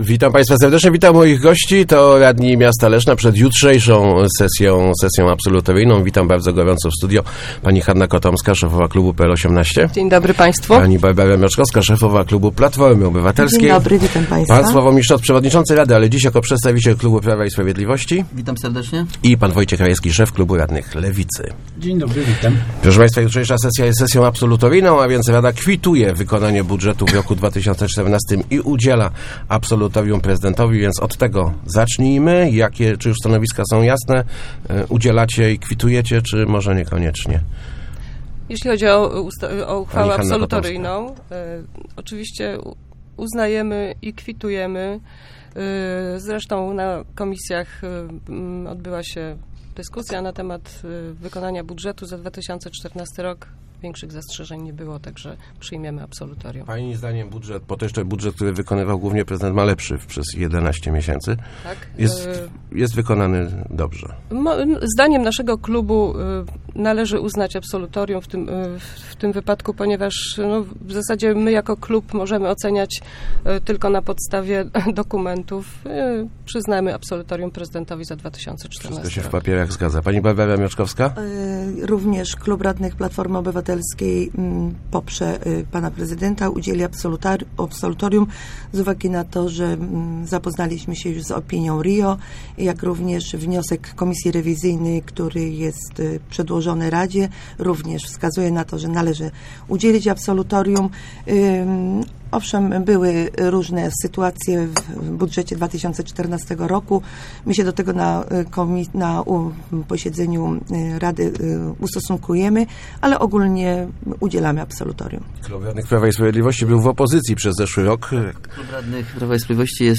Sławomir Szczot PiS Na czwartkowej sesji Rada Miejska Leszna podsumuje wykonanie bud�etu w ubieg�ym roku. Przedstawiciele klubów na antenie Radia Elka zapewnili, �e nie ma w�tpliwo�ci co do udzielenia absolutorim prezydentowi.